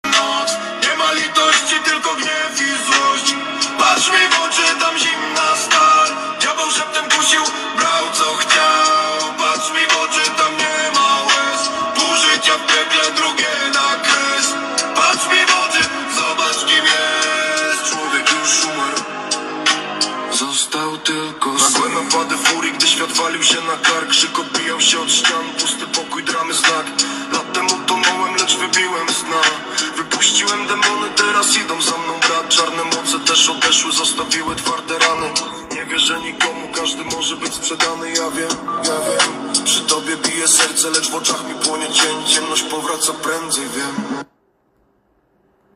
Szukam tytułu dwóch polskich piosenek rap, poniżej tekst (na dole tekst, a w załączeniu pliki dźwiękowe):